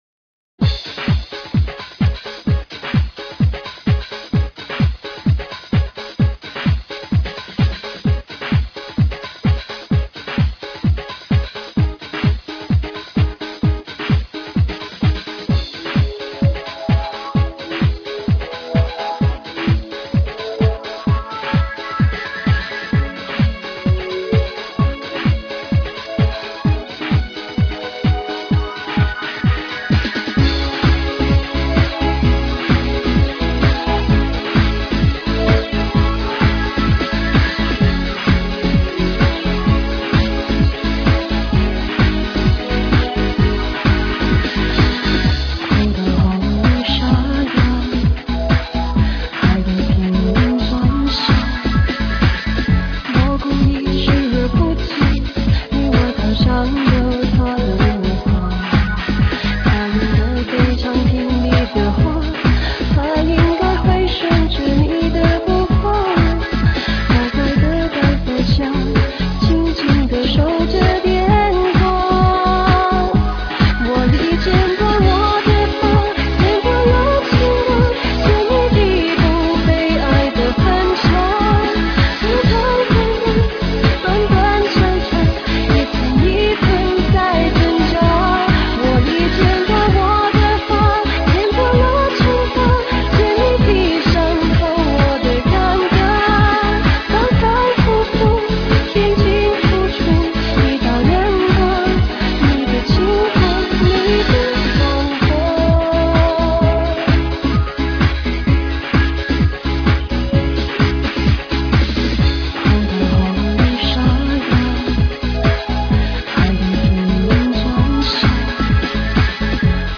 One of my fav. Cpop singers!